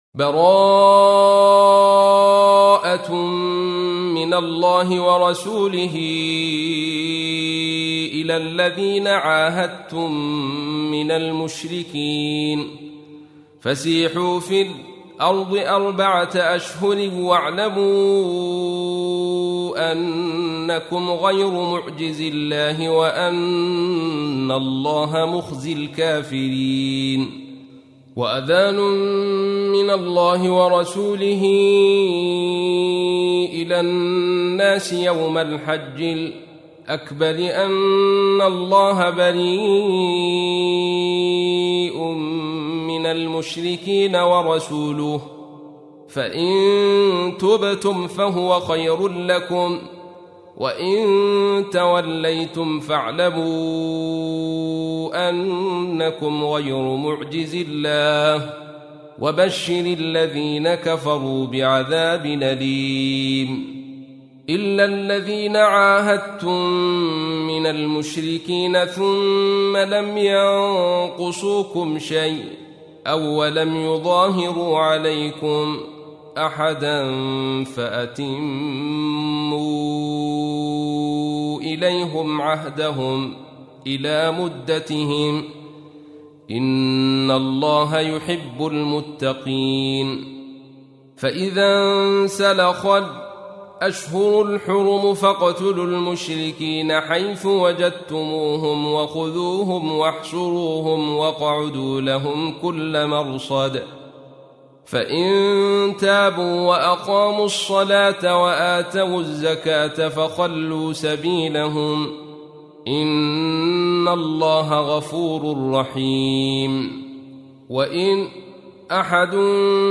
تحميل : 9. سورة التوبة / القارئ عبد الرشيد صوفي / القرآن الكريم / موقع يا حسين